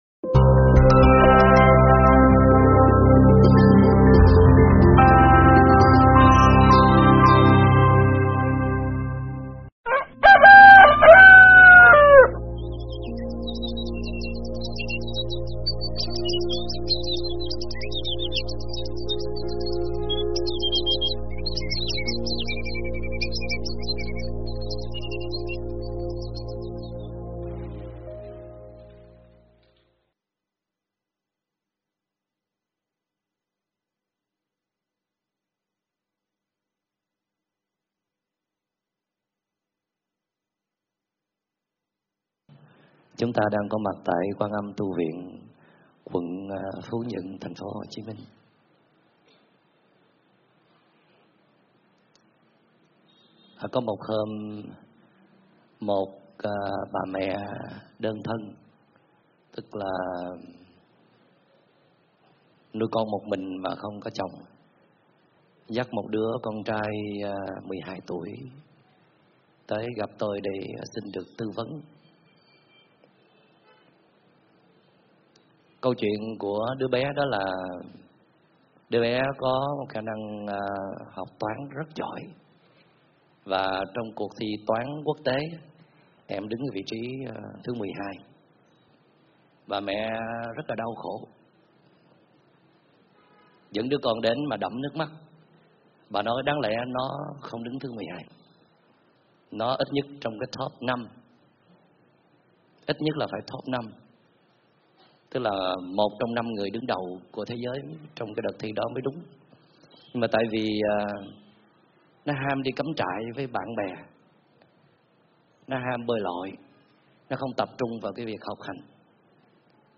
giảng tại Quan Âm tu viện
Thuyết pháp